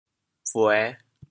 培 部首拼音 部首 土 总笔划 11 部外笔划 8 普通话 péi 潮州发音 潮州 buê5 文 潮阳 buê5 文 澄海 buê5 文 揭阳 buê5 文 饶平 buê5 文 汕头 buê5 文 中文解释 潮州 buê5 文 对应普通话: péi 在植物、墙堤等的根基部分加土：～土。